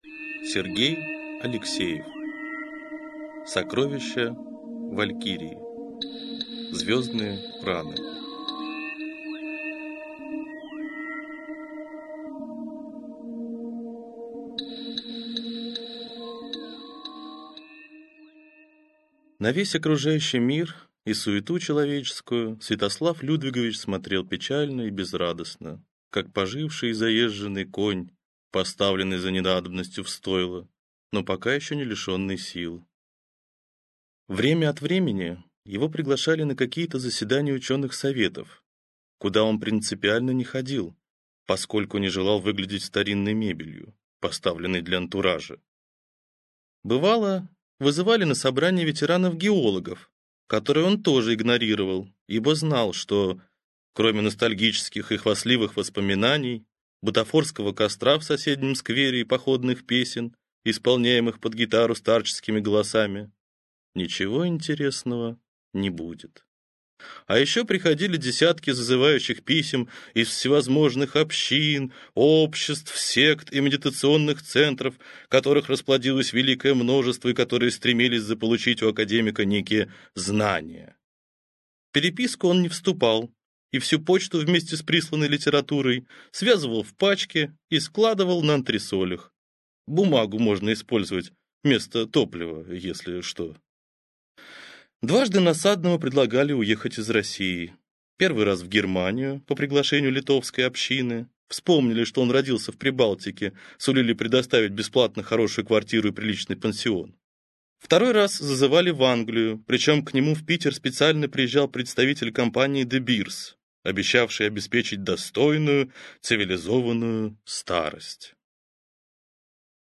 Аудиокнига Звездные раны | Библиотека аудиокниг